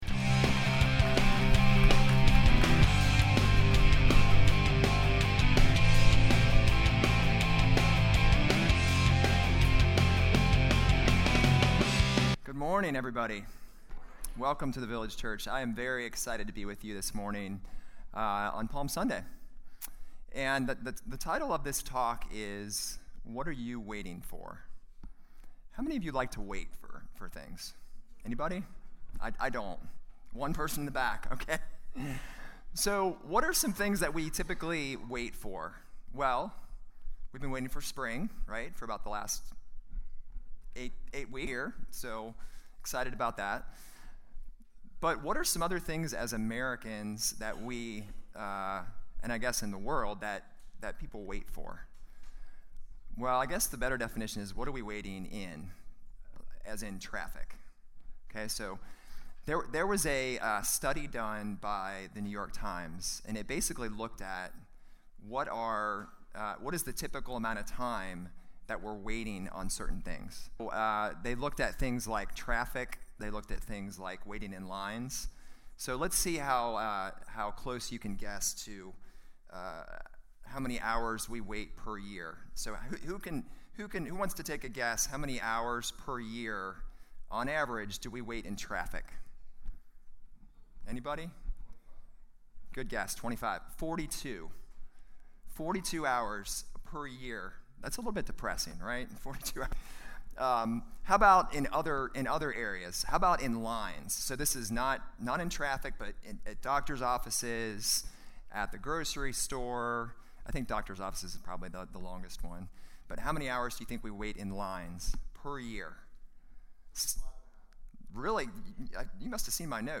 Sermons - The Village Church